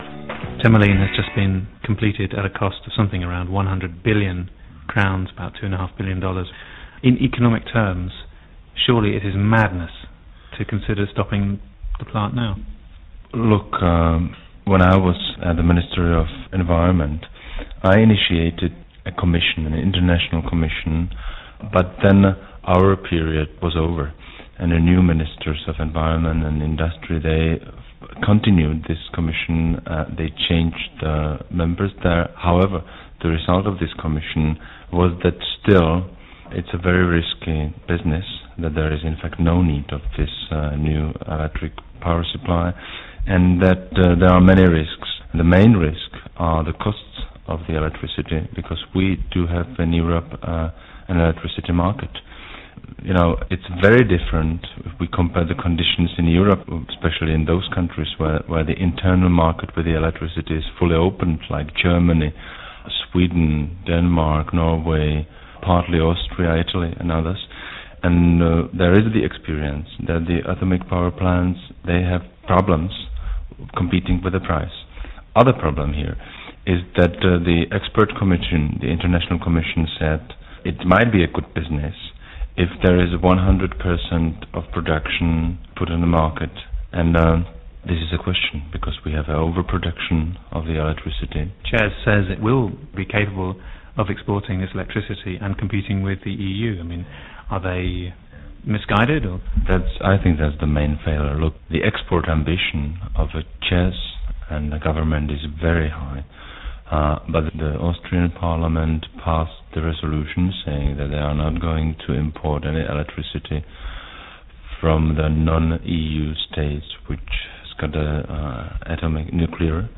Earlier this week I met Mr Bursik to discuss the economic viability of Temelin: